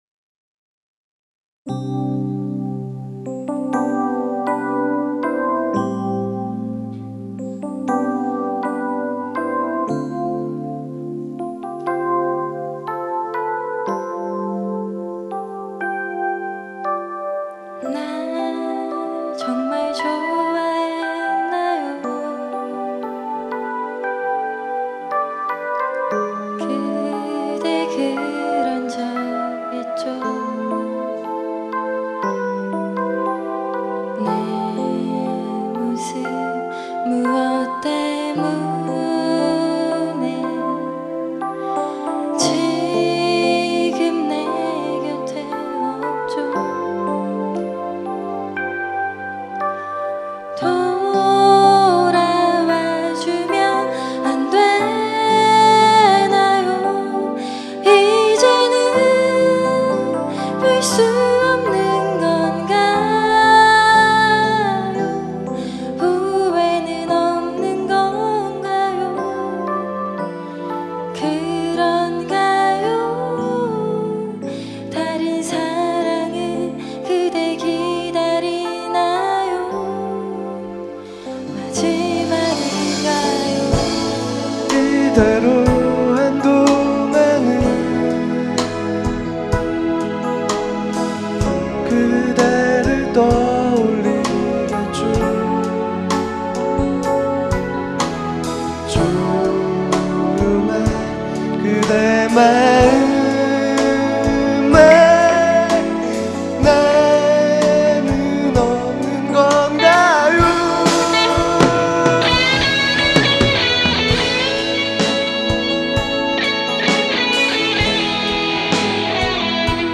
2004년 제 24회 정기 대공연
홍익대학교 신축강당
노래
일렉트릭 기타
드럼
신디사이저